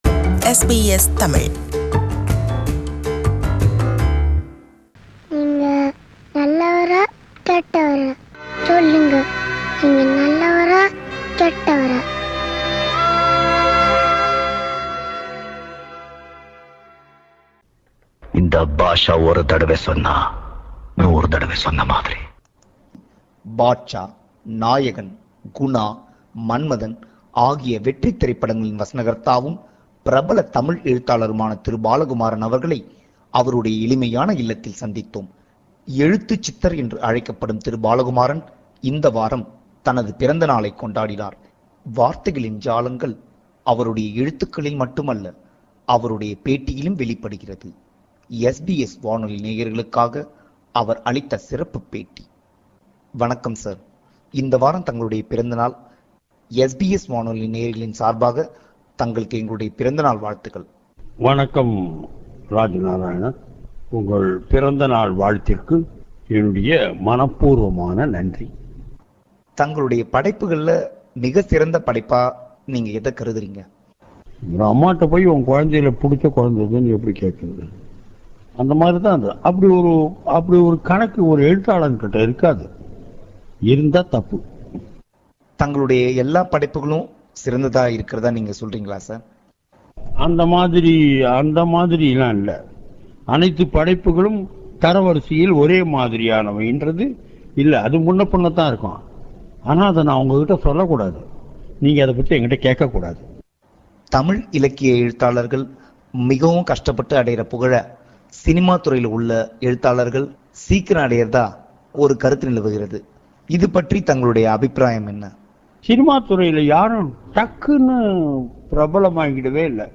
Interview with novelist Balakumaran – Part 1
It’s a rebroadcast of the interview – Part 1.